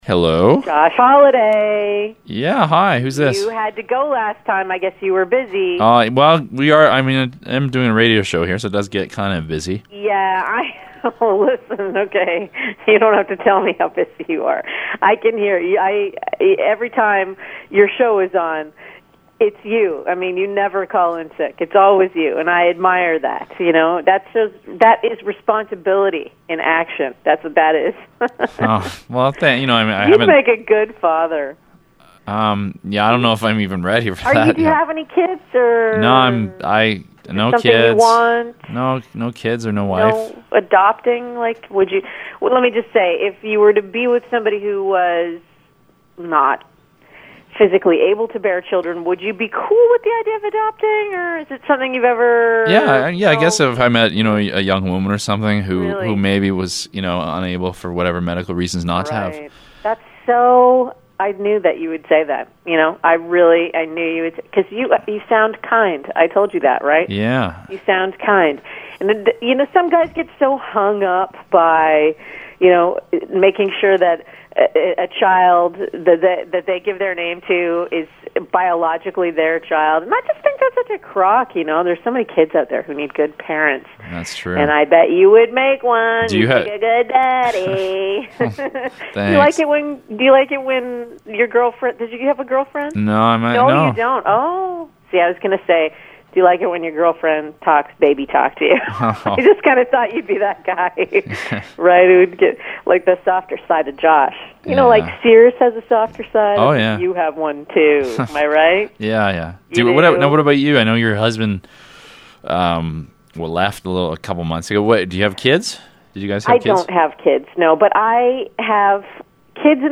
Audio, Phonecall, Stalker No Comments »